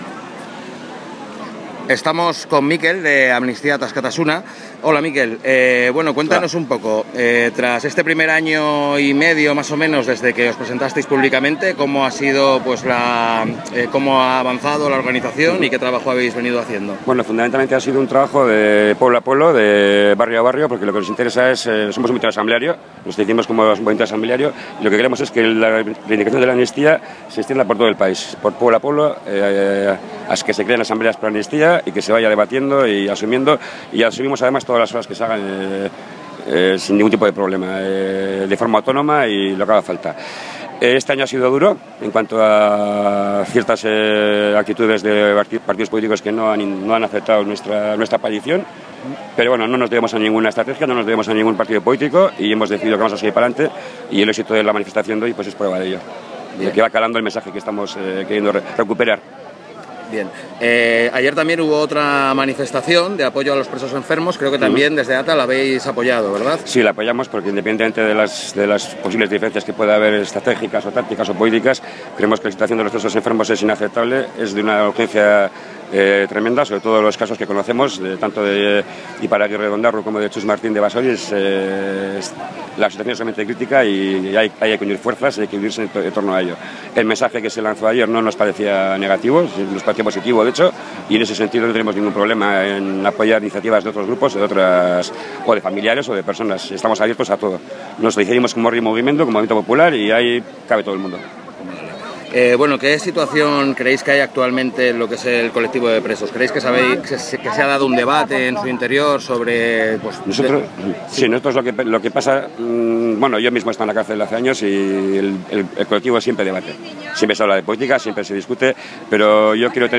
La Haine entrevista
al finalizar la manifestación que ha tenido lugar hoy en Bilbo en defensa de la amnistía.